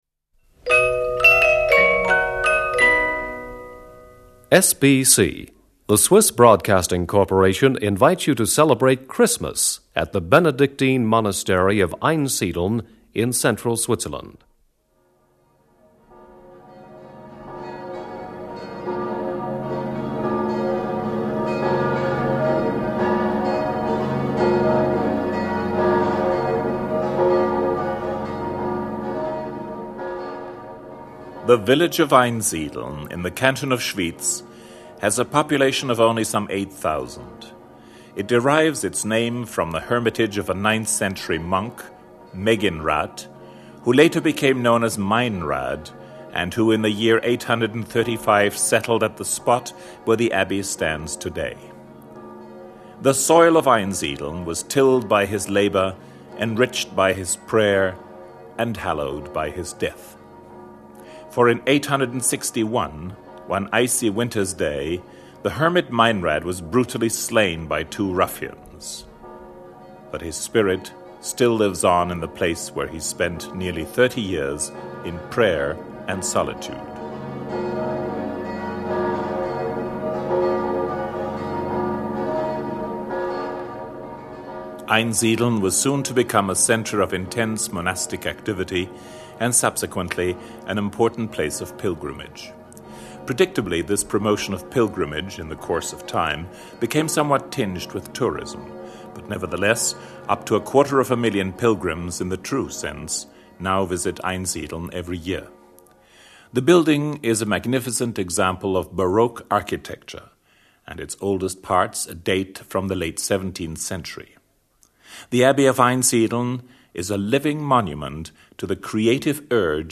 HYMNUS: Christe redemptor omnium – Christ, the Redeemer of All.